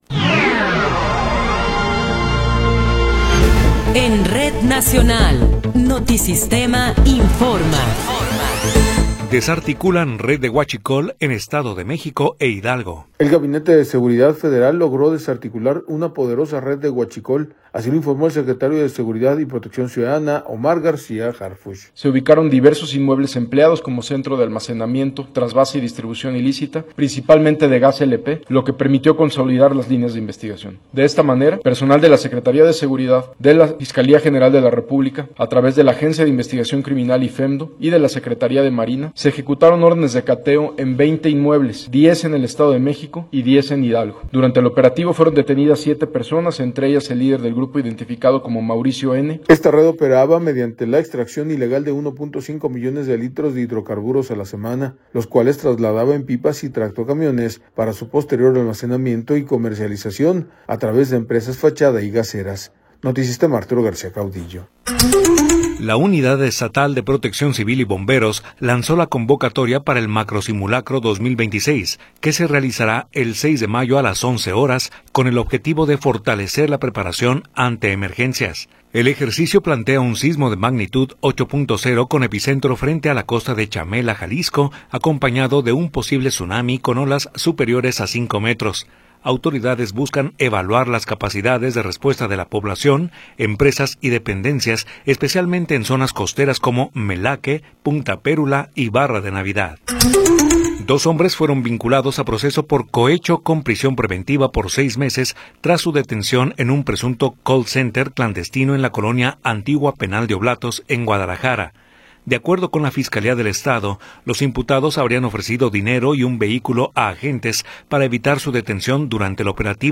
Noticiero 19 hrs. – 22 de Abril de 2026